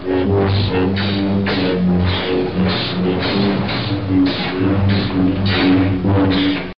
04 Star Wars - Lightsaber